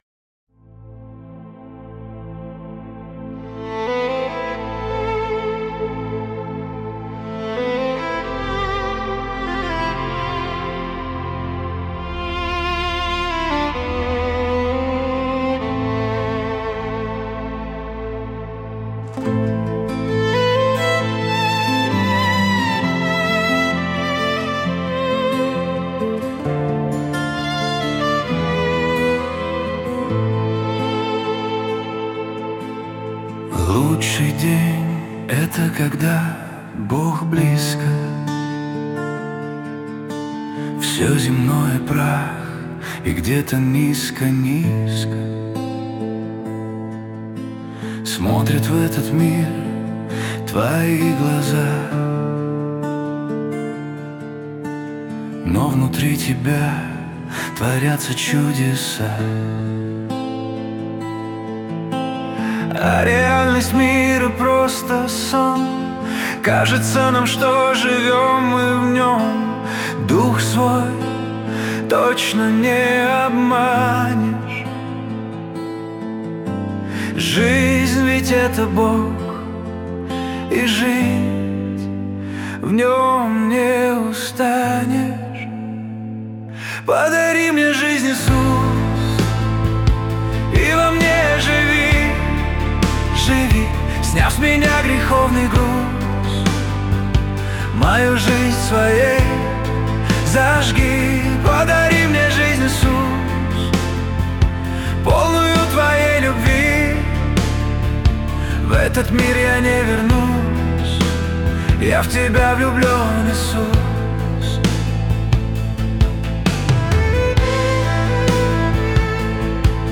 песня ai
221 просмотр 554 прослушивания 88 скачиваний BPM: 67